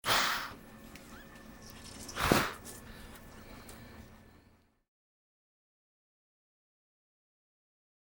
A recording of the hissing exhalations of a disturbed Gila Monster.